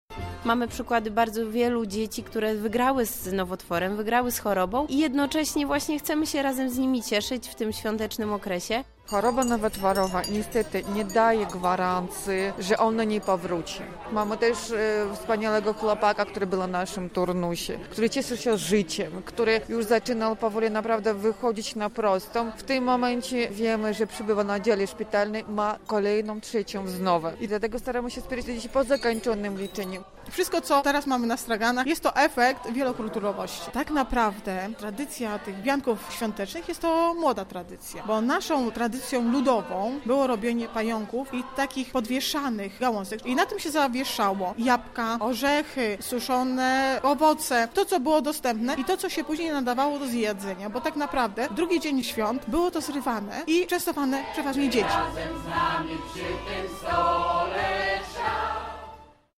Mieszkańcy Lublina wzięli udział w Kiermaszu Bożonarodzeniowym
Mieszkańcy Lublina razem kolędowali i nabywali świąteczne podarunki.